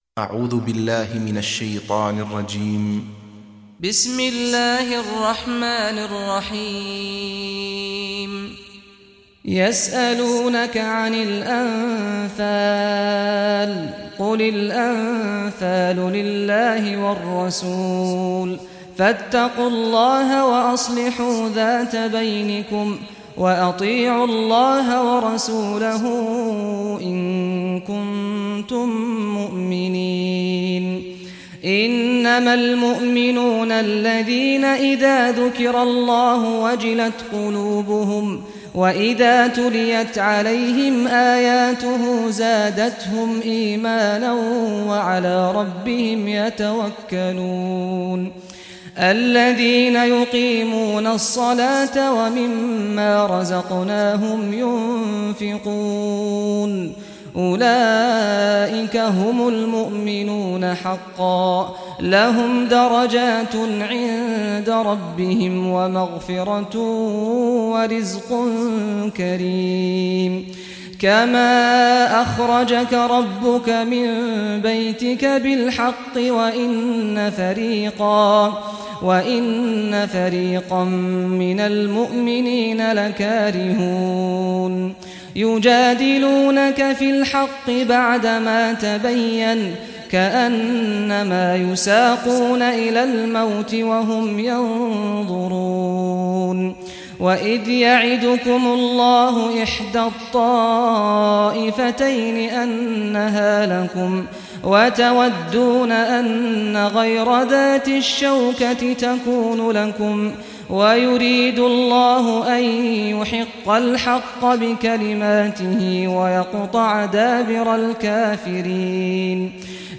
Surah Al-Anfal Recitation by Sheikh Saad Al Ghamdi
Surah Al-Anfal, listen or play online mp3 tilawat / recitation in Arabic in the beautiful voice of Imam Sheikh Saad Al Ghamdi.